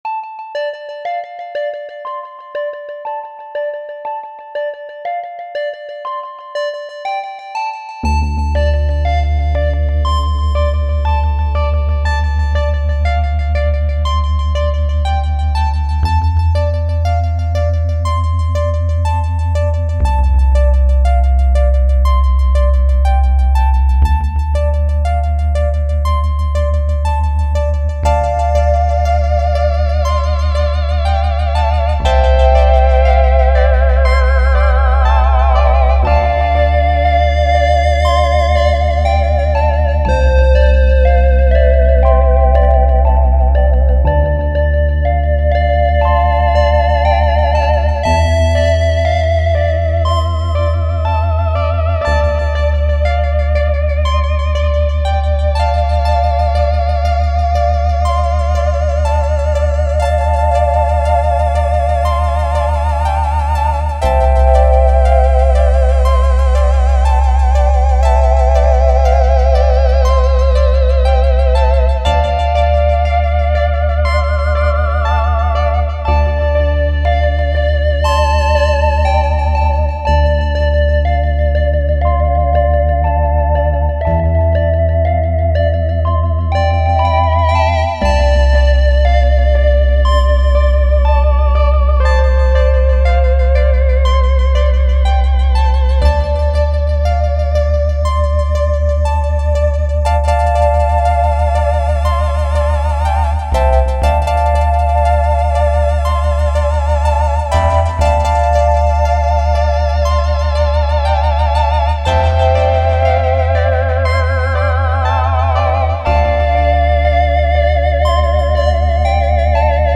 Torso T-1 and TubeOhm Jeannie work fine together and are great fun to play around with :upside_down_face:.